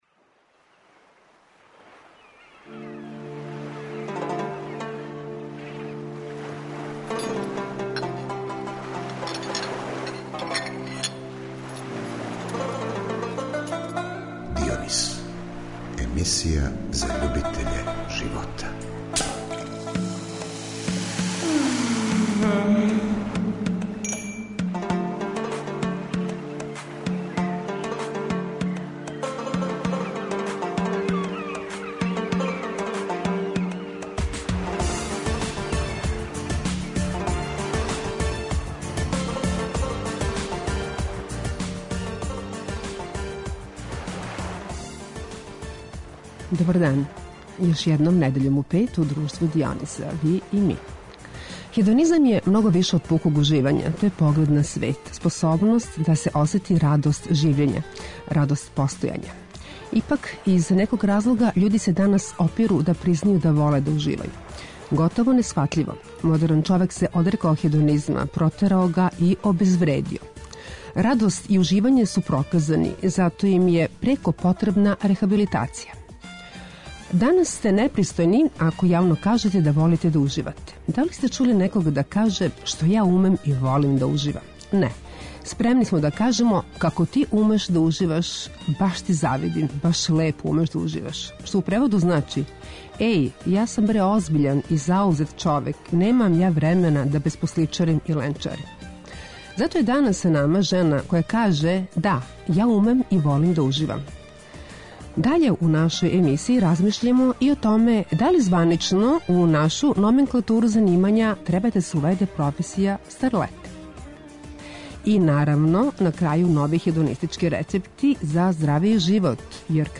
Зато је данас с нама женa која каже: Да, ја умем и волим да уживам... и, наравно, други хедонистички рецепти за здравији живот, јер хедонизам јача организам!